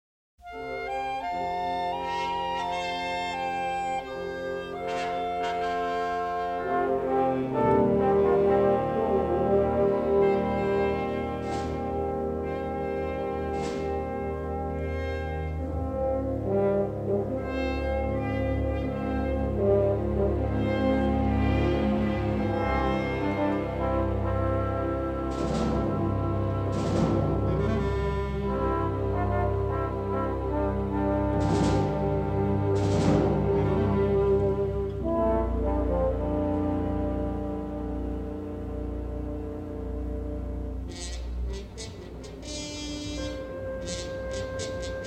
in stereo sound